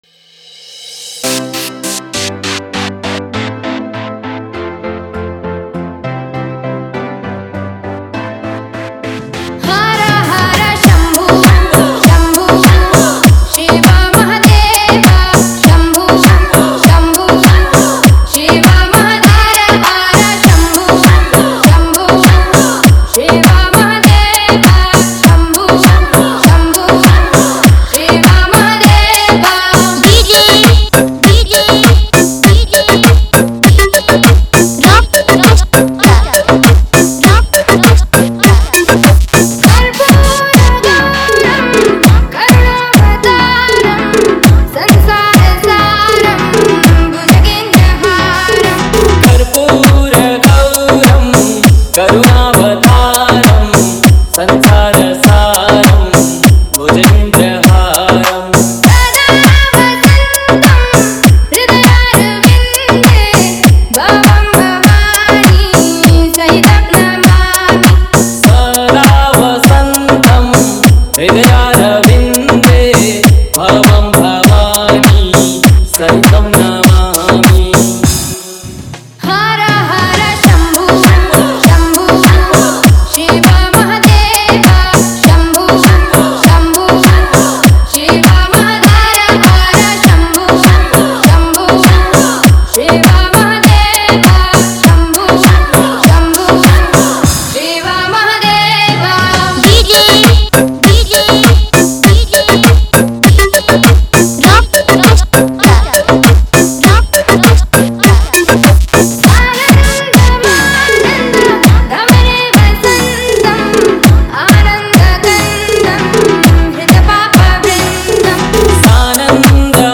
Category:  Bol Bam 2023 Dj Remix Songs